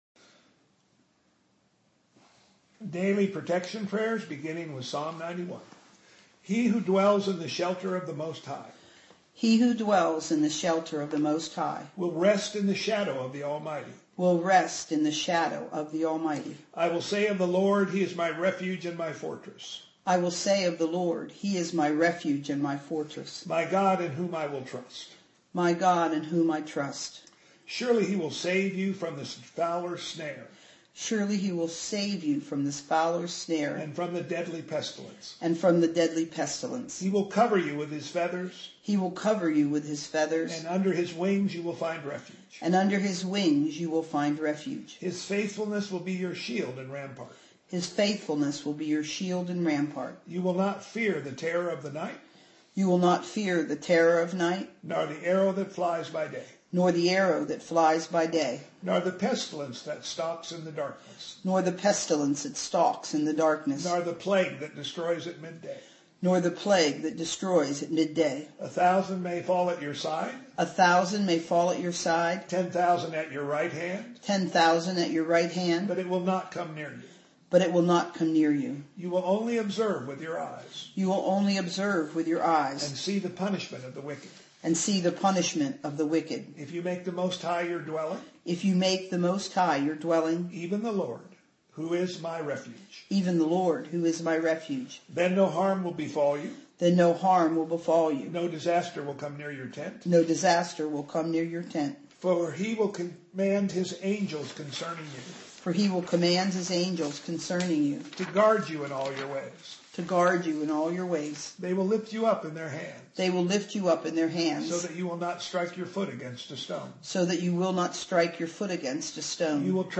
Here are some classic protection prayers that have been used by saints through the ages. You can listen or read them.